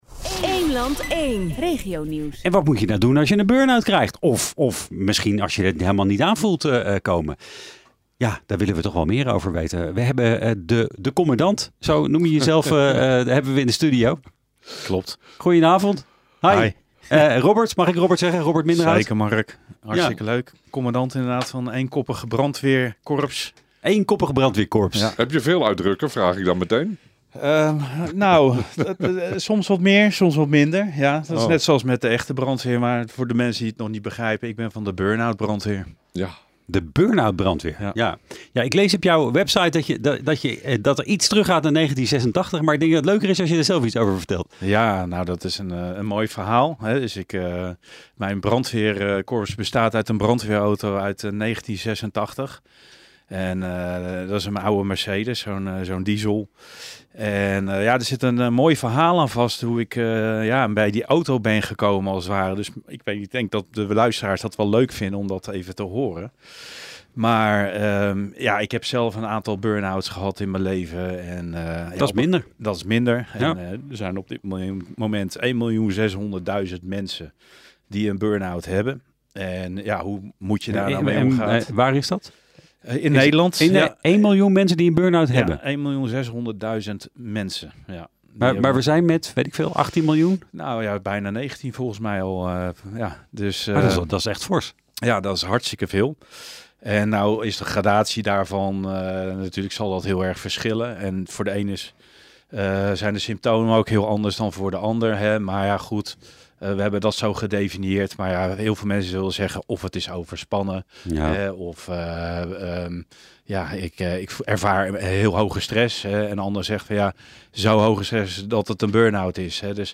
Dit is een lokale zender die te ontvangen is in Baarn, Soest, Soesterberg en Bunschoten en daar werd ik ondervraagd over de werkzaamheden van de Burn-Out Brandweer.